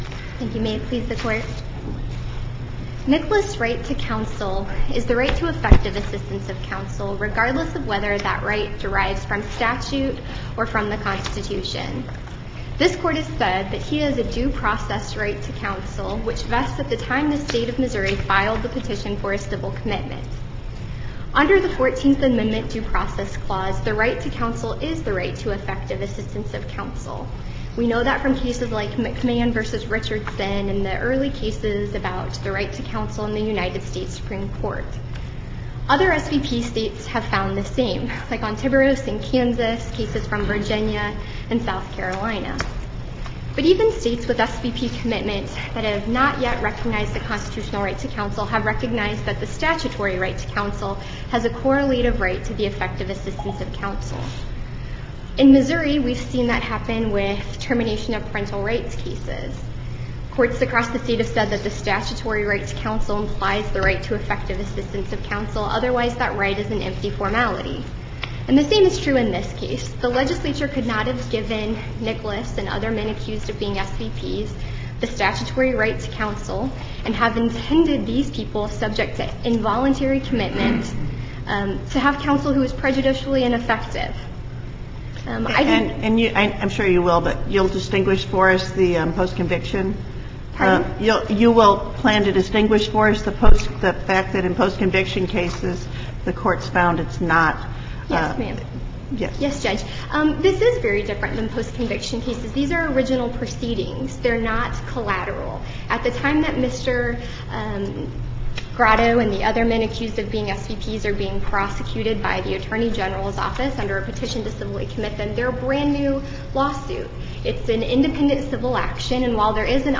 MP3 audio file of oral arguments before the Supreme Court of Missouri in SC96830